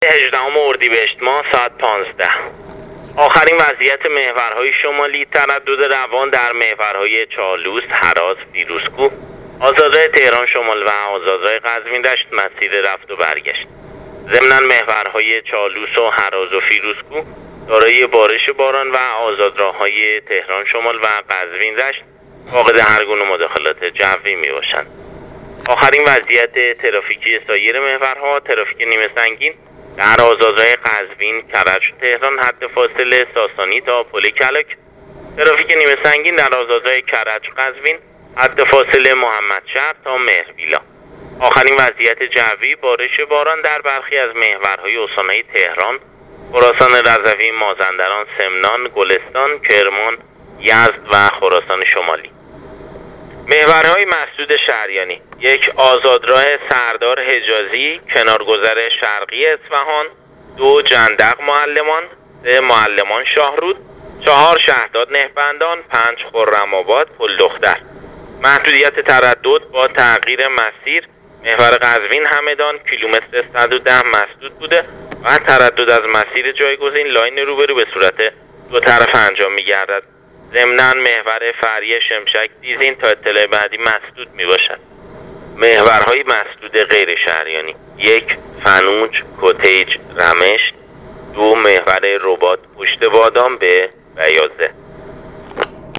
گزارش رادیو اینترنتی از آخرین وضعیت ترافیکی جاده‌ها تا ساعت ۱۵ هجدهم اردیبهشت